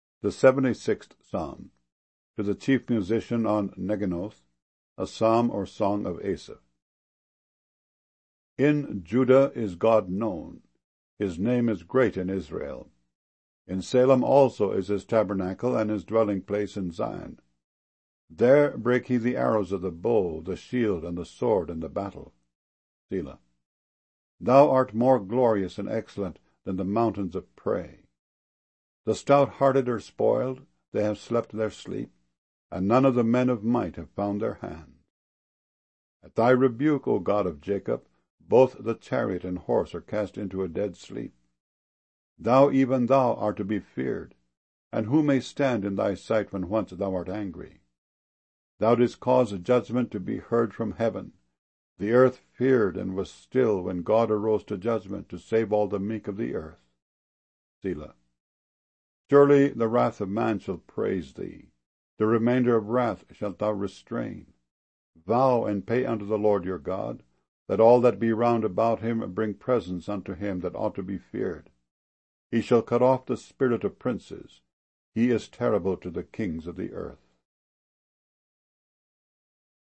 MP3 files mono 32 kbs small direct from wav files